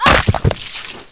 FMediumImpact1.WAV